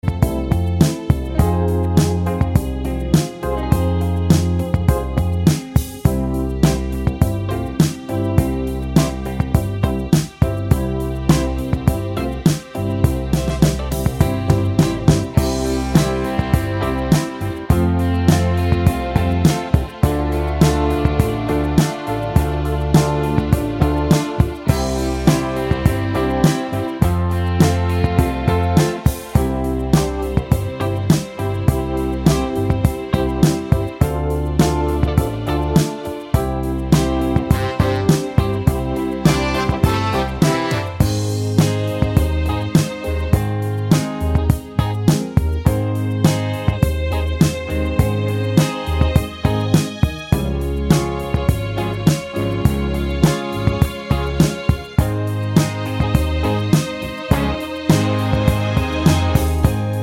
no Backing Vocals Duets 4:07 Buy £1.50